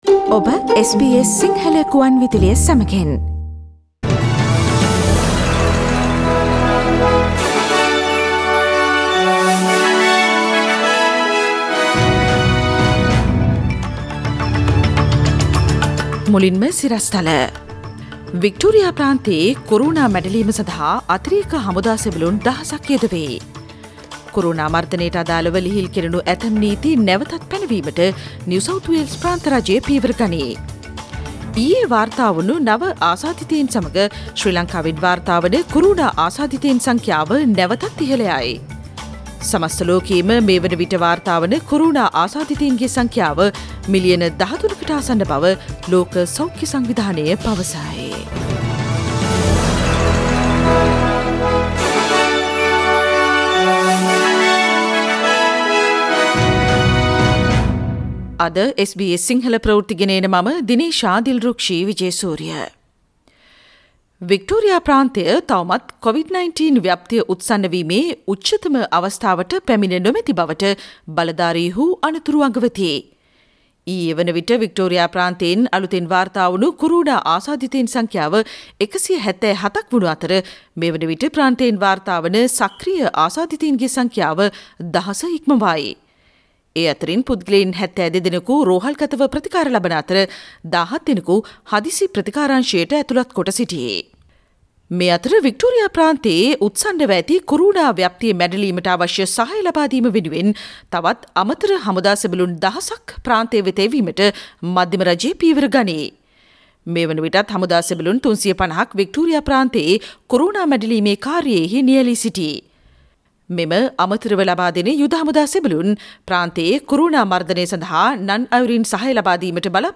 Daily News bulletin of SBS Sinhala Service: Tuesday 14 July 2020
Today’s news bulletin of SBS Sinhala radio – Tuesday 14 July 2020.